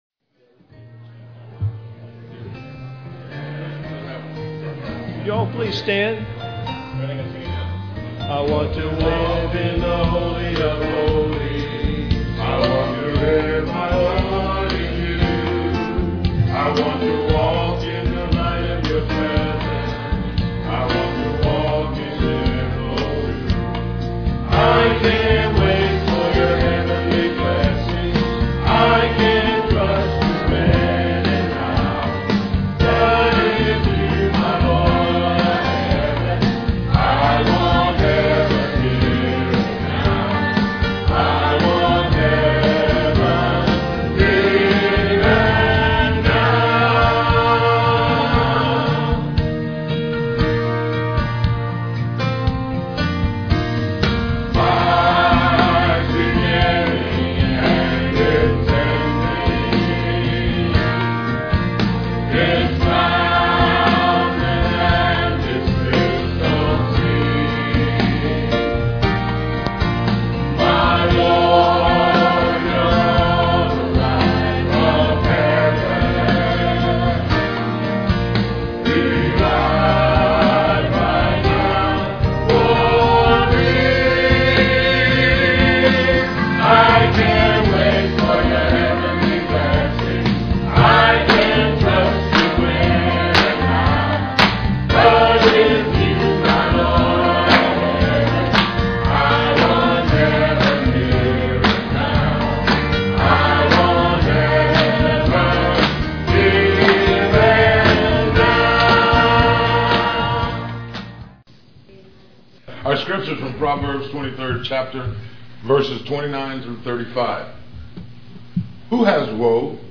"He Lifted Me" piano and organ duet